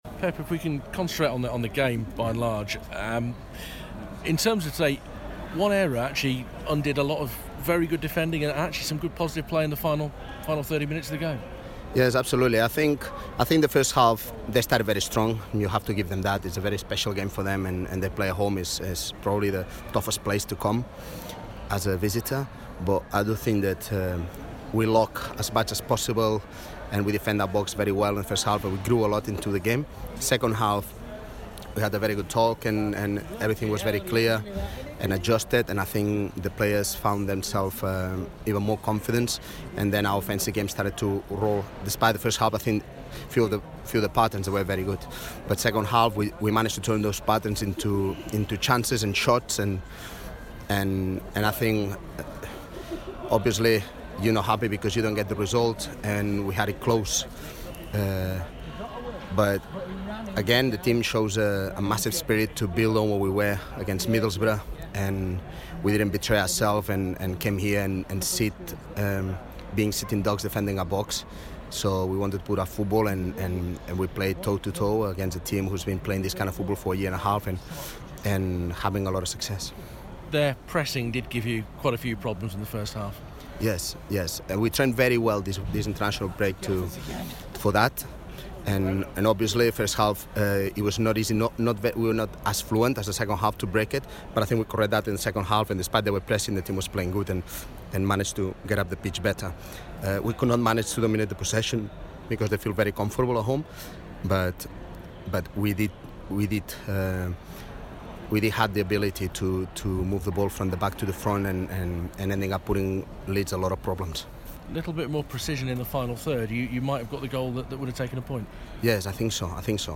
LISTEN: Blues head coach Pep Clotet reacts to the 1-0 away defeat to Leeds